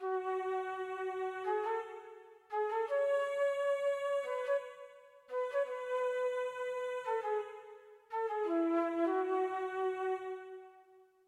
长笛
Tag: 85 bpm Hip Hop Loops Flute Loops 1.90 MB wav Key : Unknown